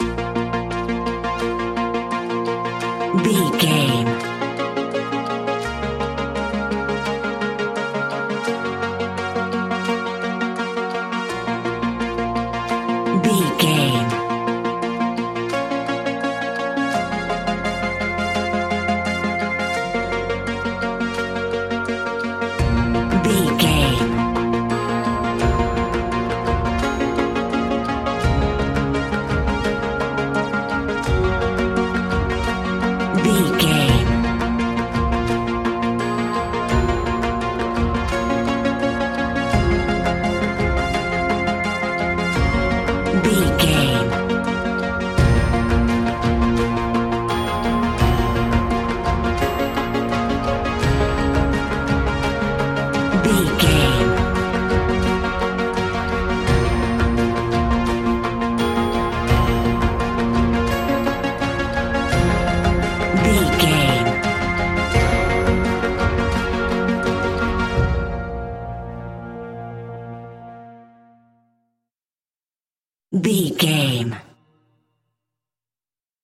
In-crescendo
Thriller
Aeolian/Minor
tension
ominous
dark
haunting
eerie
horror music
Horror Pads
horror piano
Horror Synths